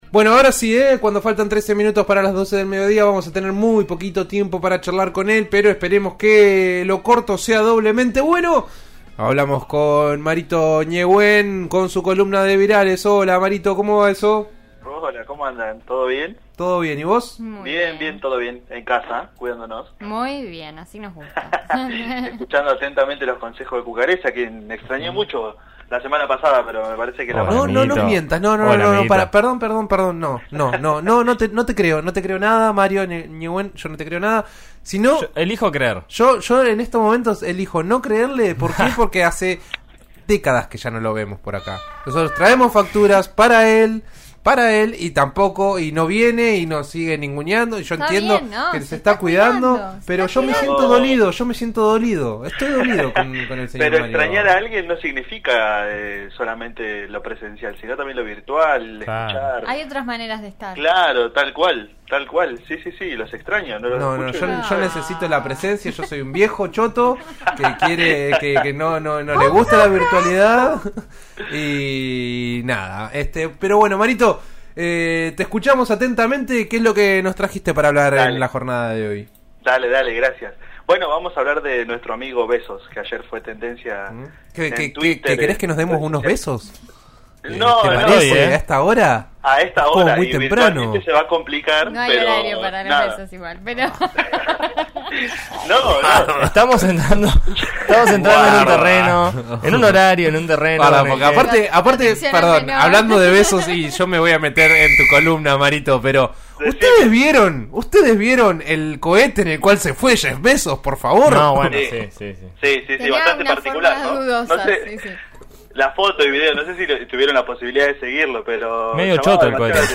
columnista sobre virales.